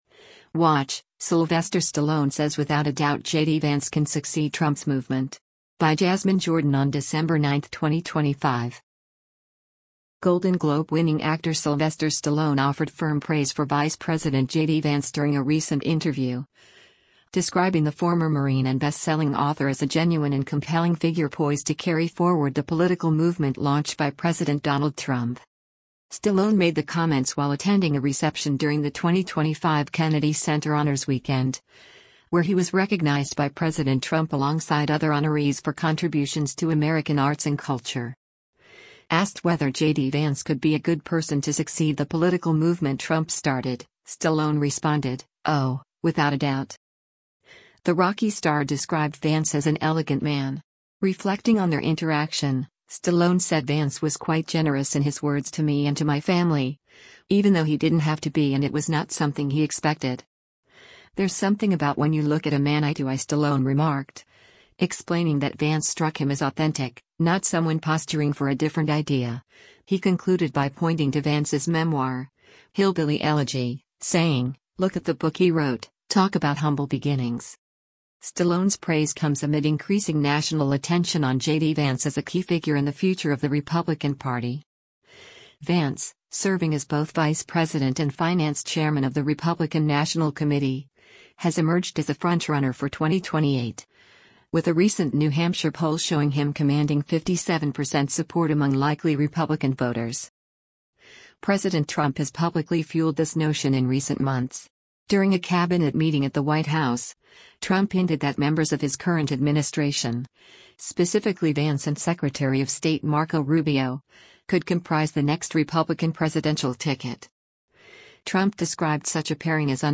Golden Globe-winning actor Sylvester Stallone offered firm praise for Vice President JD Vance during a recent interview, describing the former Marine and bestselling author as a genuine and compelling figure poised to carry forward the political movement launched by President Donald Trump.
Stallone made the comments while attending a reception during the 2025 Kennedy Center Honors weekend, where he was recognized by President Trump alongside other honorees for contributions to American arts and culture.